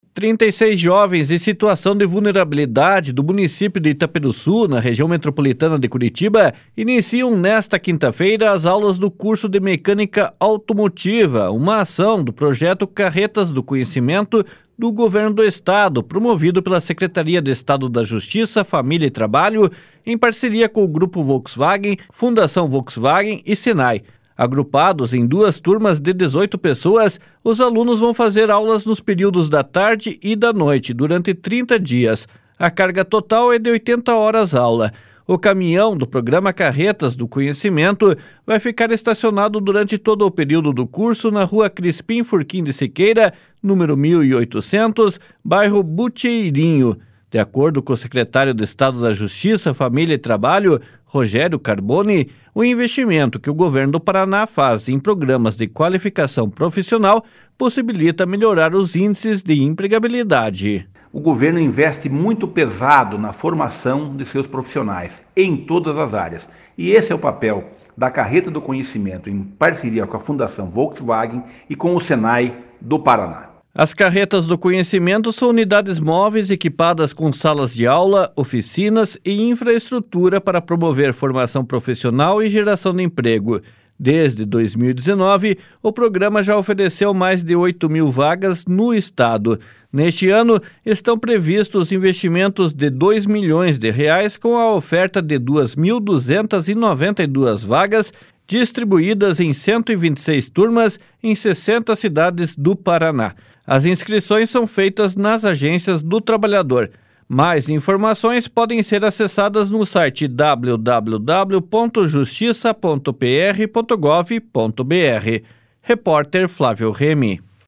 De acordo com o secretário de Estado da Justiça, Família e Trabalho, Rogério Carboni, o investimento que o Governo do Paraná faz em programas de qualificação profissional possibilita melhorar os índices de empregabilidade. //SONORA ROGÉRIO CARBONI//